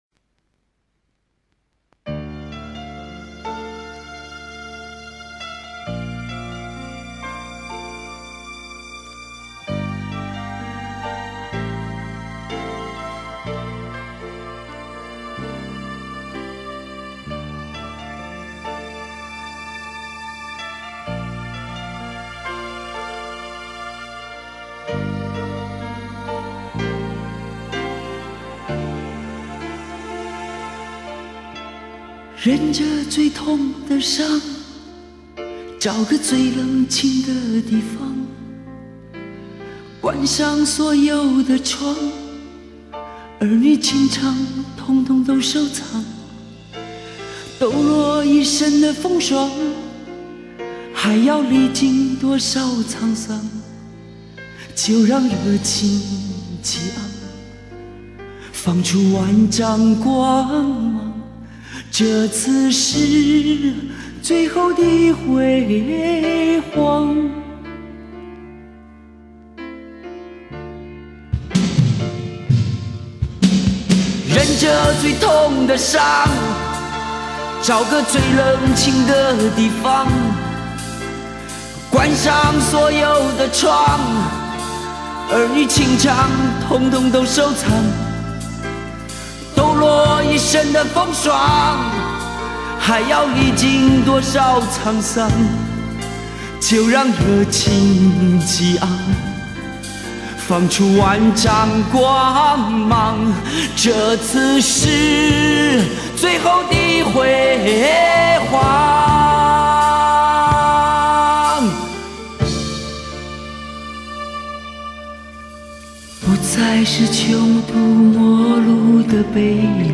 台湾80年代歌手